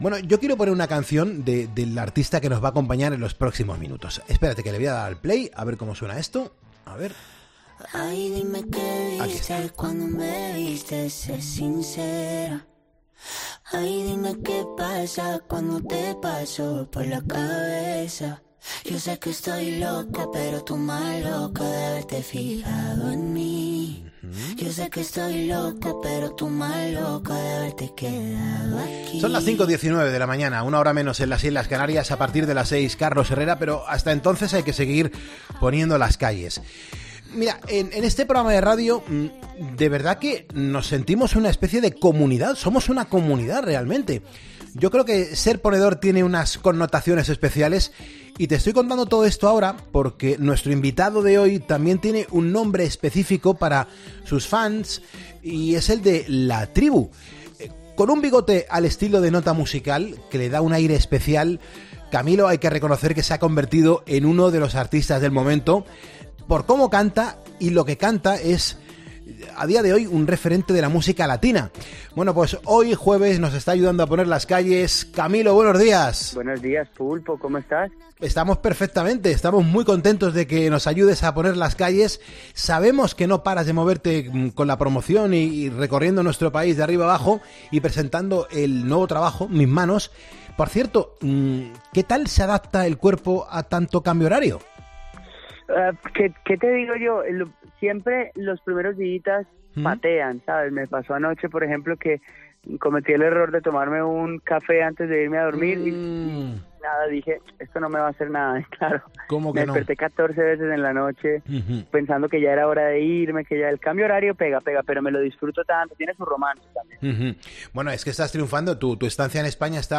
Su música ya suena en todas partes y su personalidad arrollante ha dejado una refrescante entrevista.